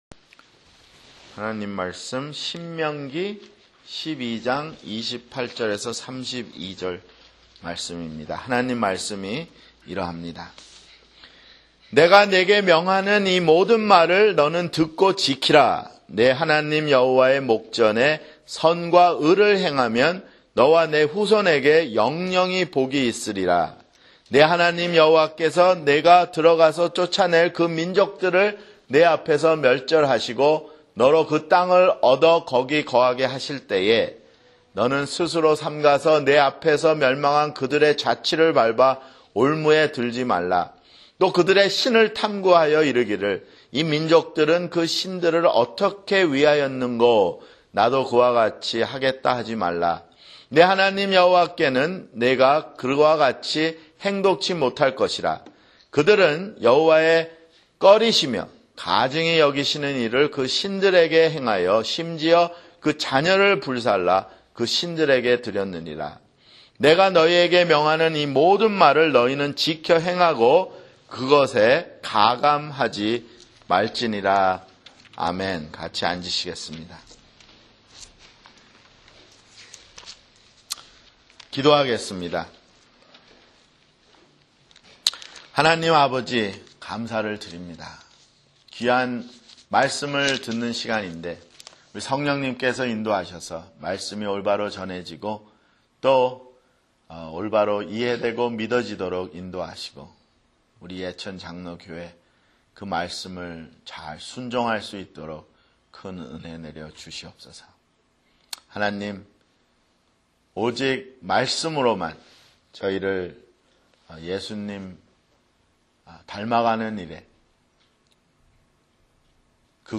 [주일설교] 예배 (3)